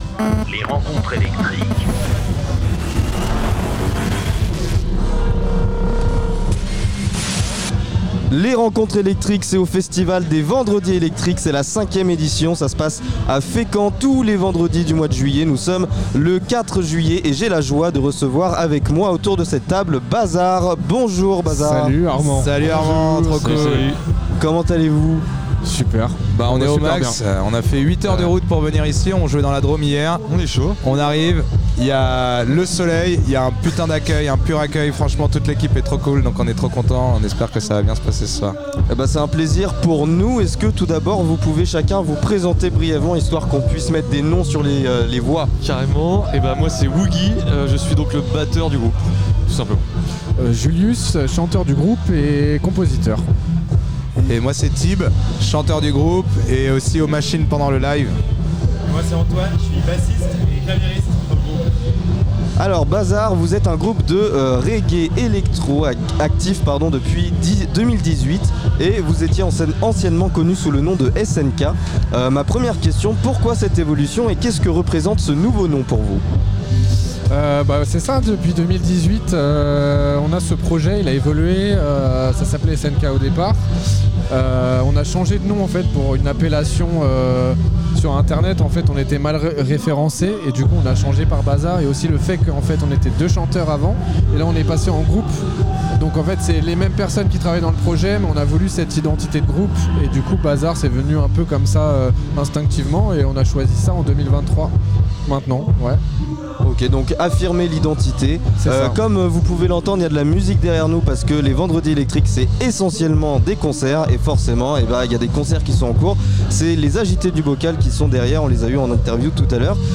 Les rencontres électriques sont les interviews des artistes régionaux qui se produisent lors du festival "Les vendredi électriques" organisés par l'association Art en Sort.